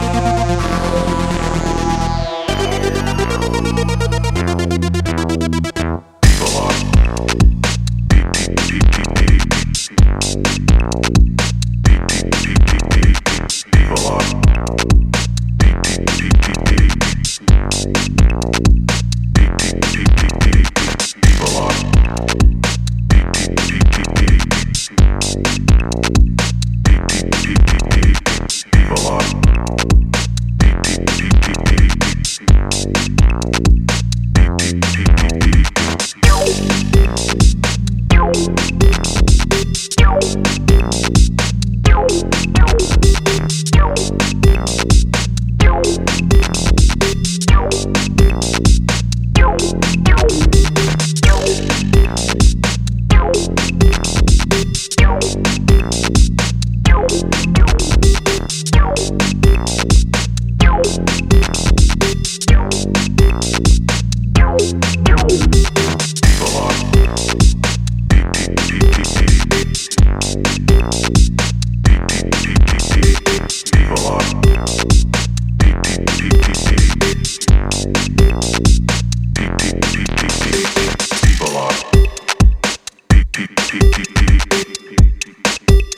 Spanish duo
two playful cuts